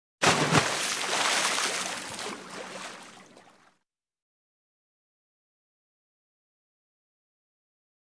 Large Splash Or Dive Into Water 1.